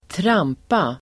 Uttal: [²tr'am:pa]